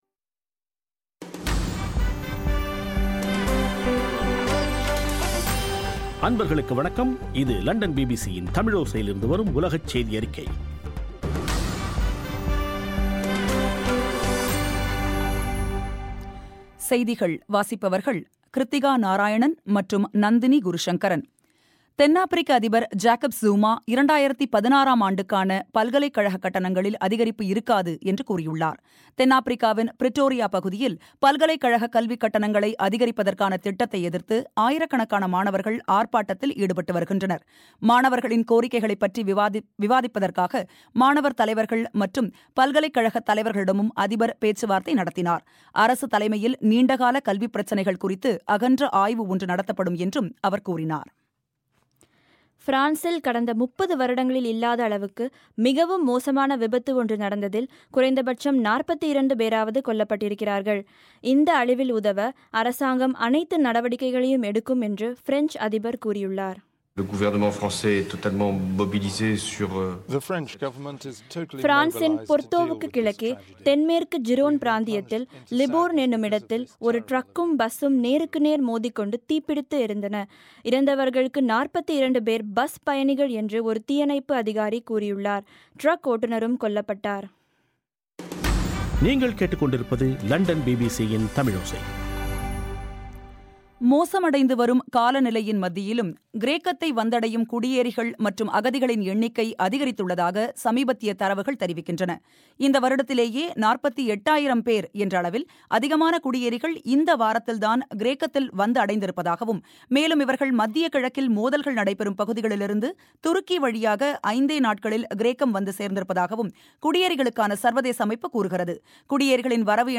அக்டோபர் 23, 2015 பிபிசி தமிழோசையின் உலகச் செய்திகள்